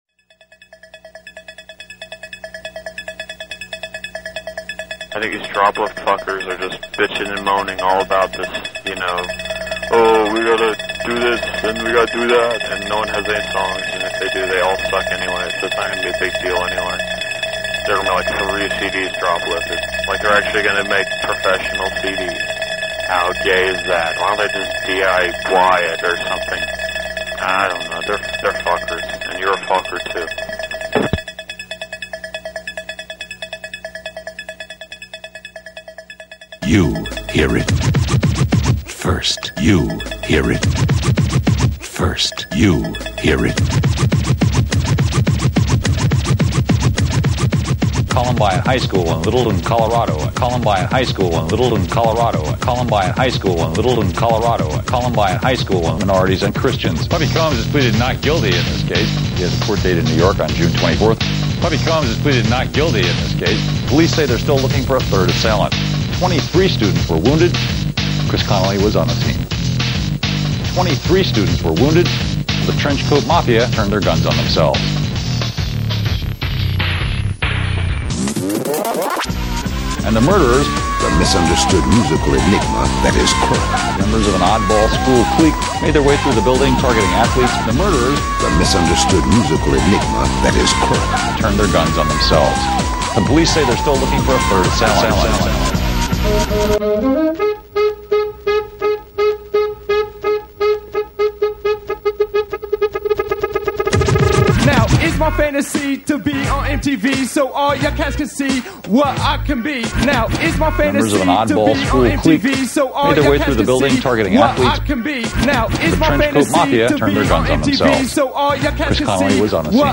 Collage of Celebrity Speech
This is a gallery of collage derived from the utterings of media celebrities, created by artists from around the planet.
Creator: Social Security